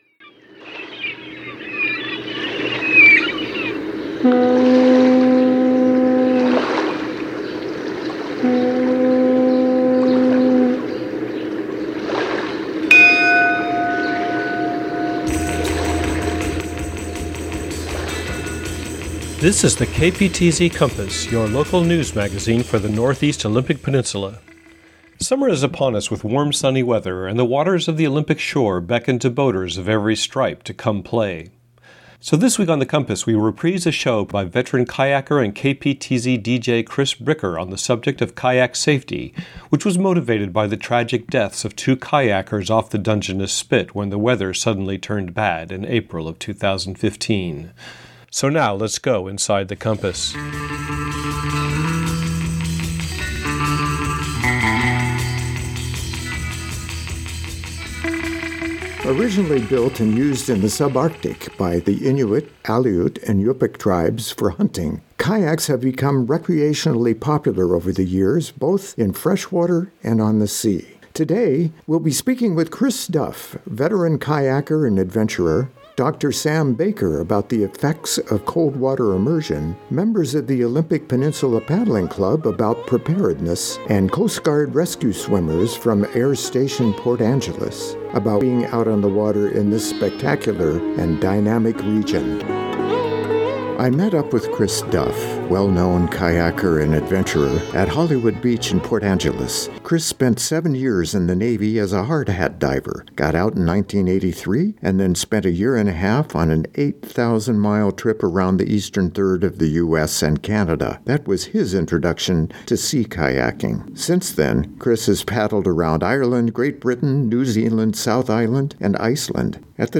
Genre: Radio News.